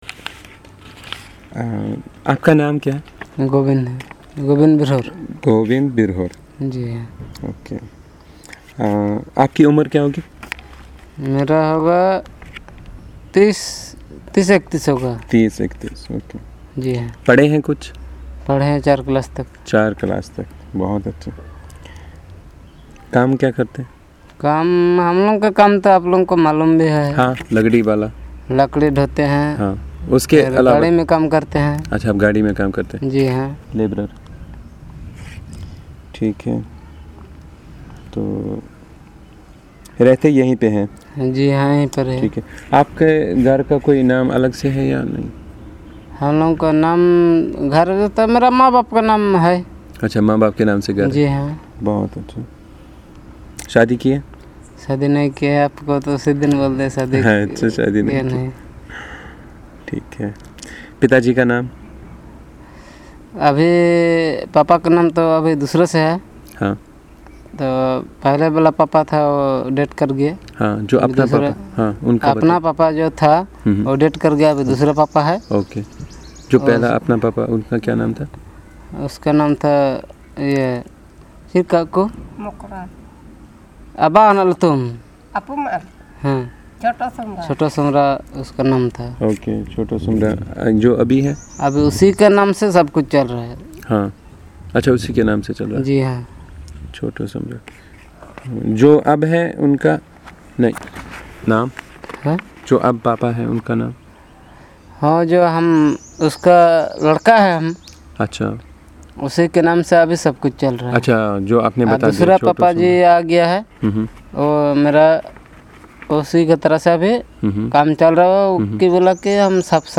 Discussion about the lifestyle, literacy, religion, customs, occupations, money, trade, etc. in Birhor